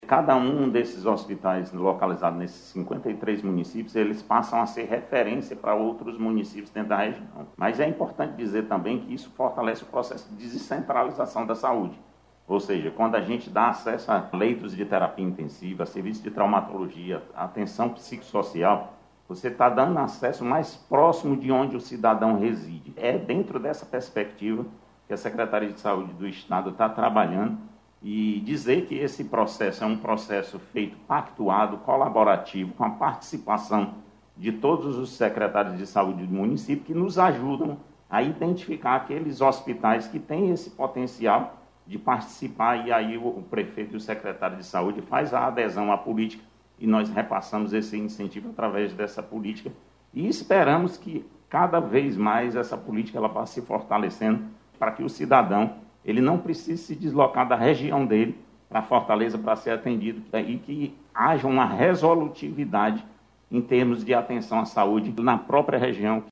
O secretário da Saúde, Marcos Gadelha, reconhece que a nova política fortalece o modelo de descentralização da Saúde no Ceará, dentro plano de modernização.